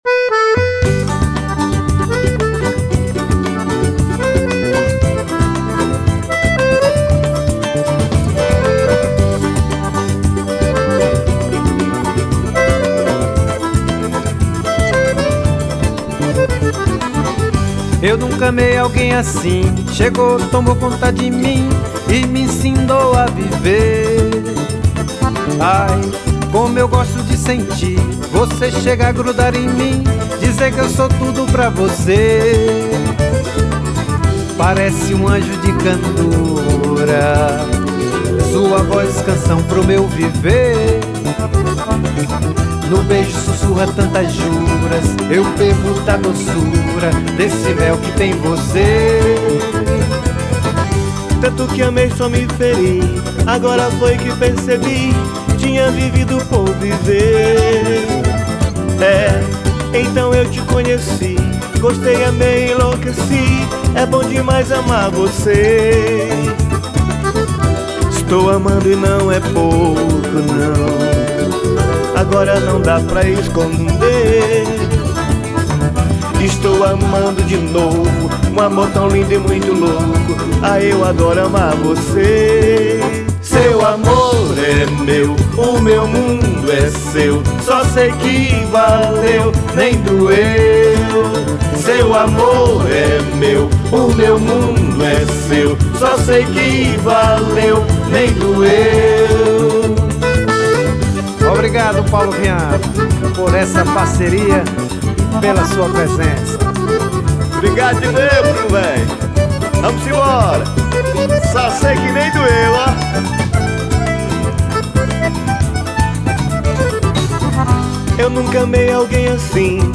1448   03:41:00   Faixa:     Forró